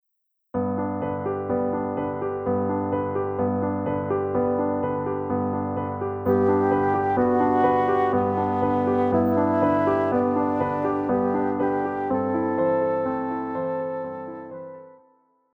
Classical
Vocal - female,Vocal - male
Piano
Voice with accompaniment